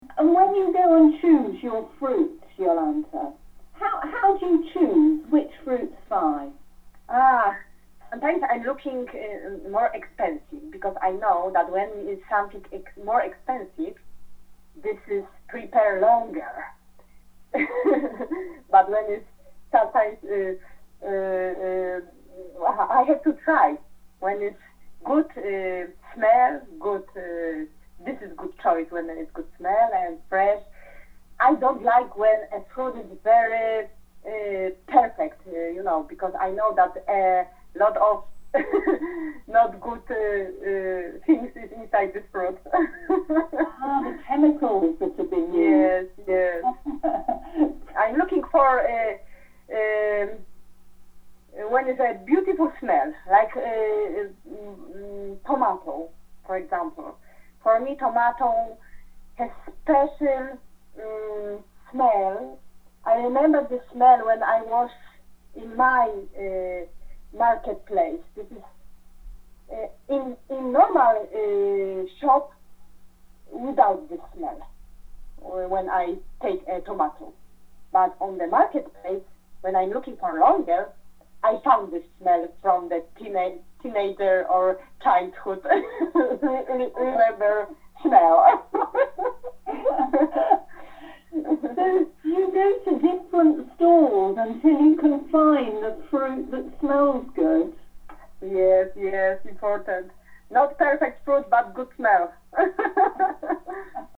Oral History Interviews – Market
Listen below to audio clips taken from interviews conducted by our volunteers with the community reflecting on 800 years of Loughborough’s market.